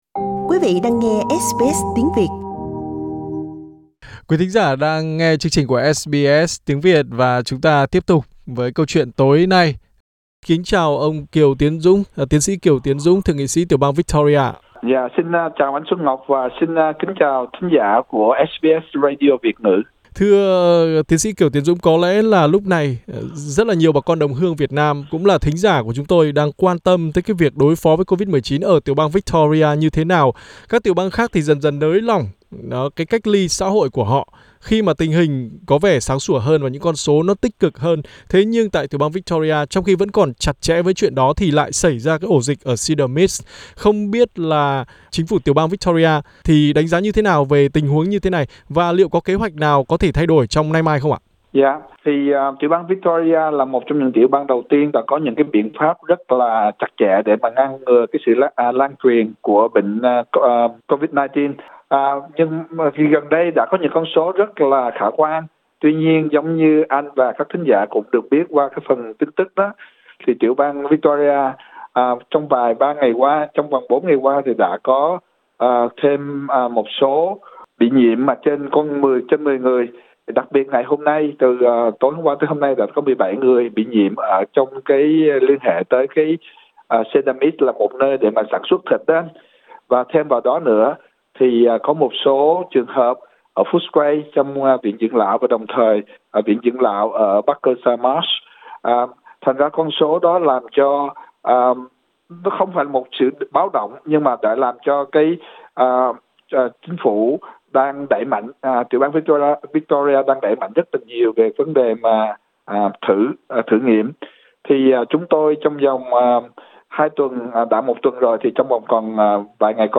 Thượng nghị sĩ tiểu bang Victoria Kiều Tiến Dũng, trong cuộc phỏng vấn với SBS Tiếng Việt đã cung cấp các thông tin cập nhật về chính sách đối phó với COVID-19 trong thời điểm hiện nay.